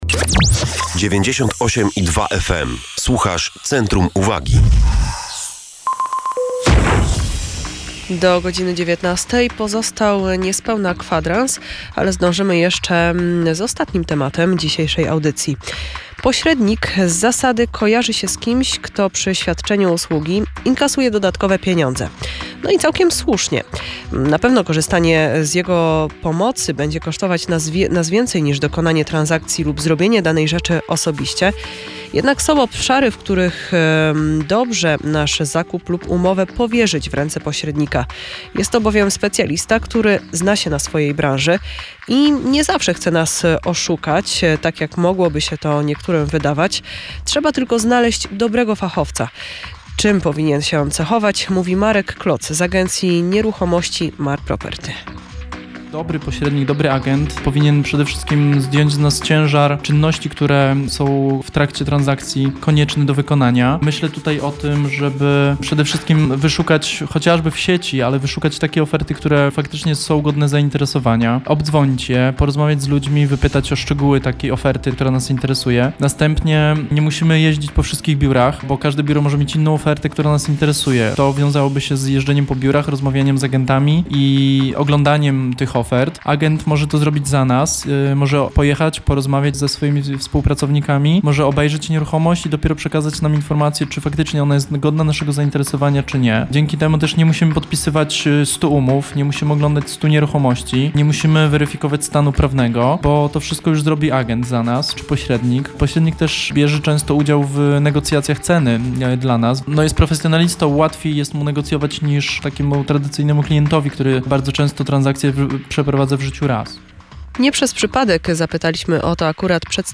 Radio Centrum – Centrum uwagi – wywiad z okazji Dnia Pośrednika